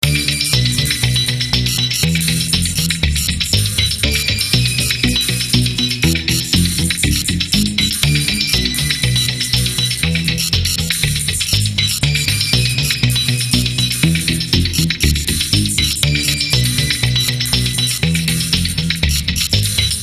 描述：沉重的步进式低音槽
标签： 120 bpm Chill Out Loops Groove Loops 3.37 MB wav Key : Unknown
声道立体声